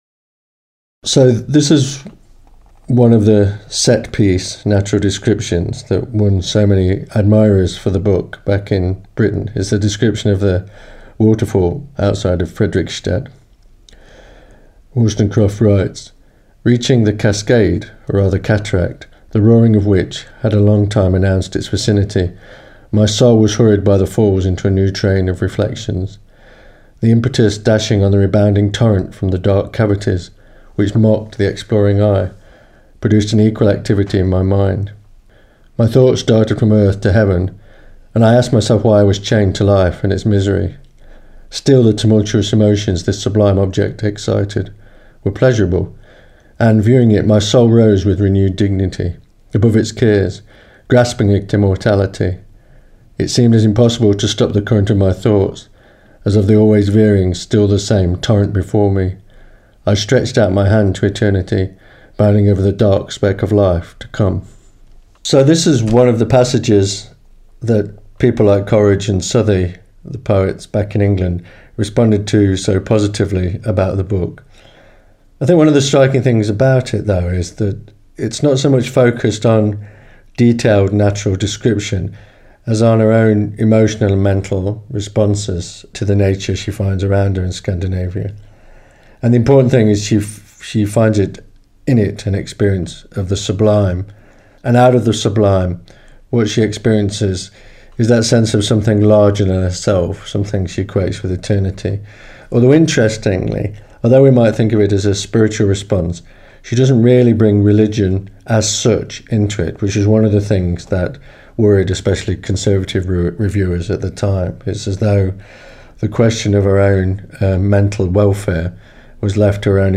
reads a short extract from the book which much impressed Wollstonecraft's contemporaries